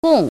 gòng gōng